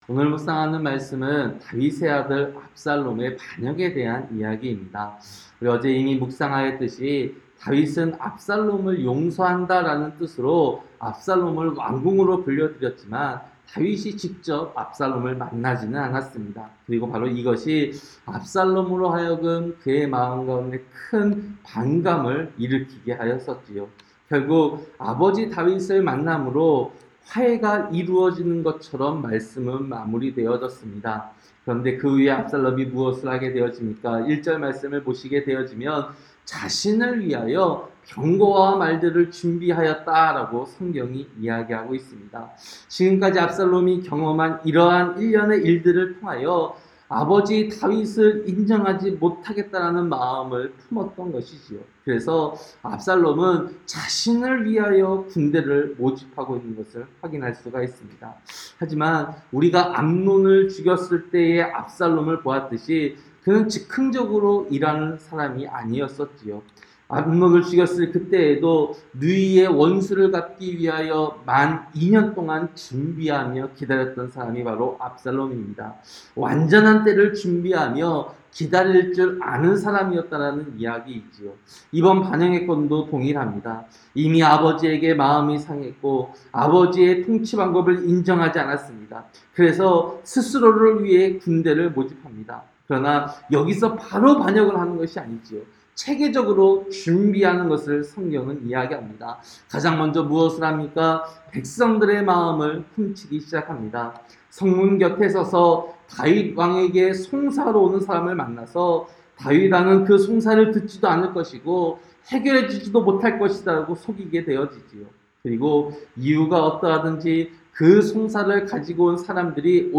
새벽설교-사무엘하 15장